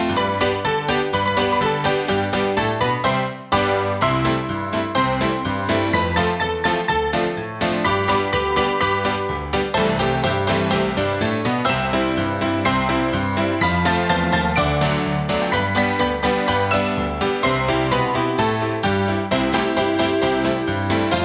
Piano Only Version Easy Listening 1:43 Buy £1.50